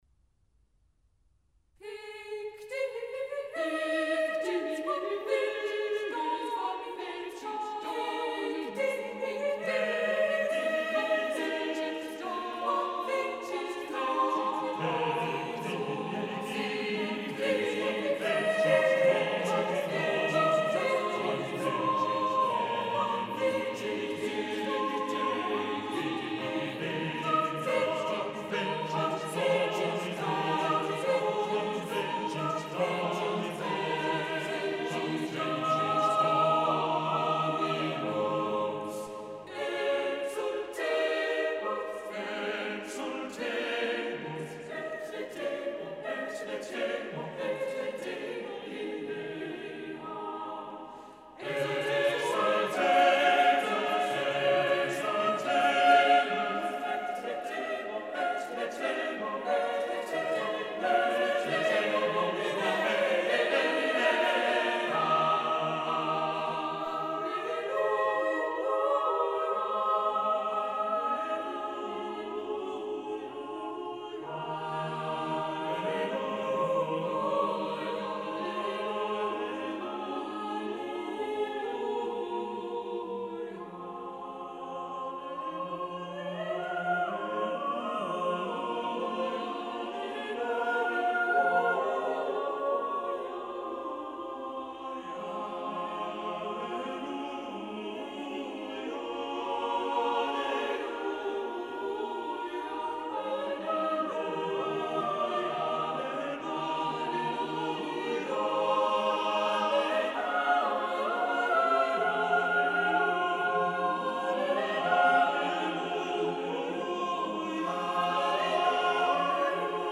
Listen to the Cambridge Singers perform "Haec Dies" by William Byrd (1543-1623), based on Psalm 118:24.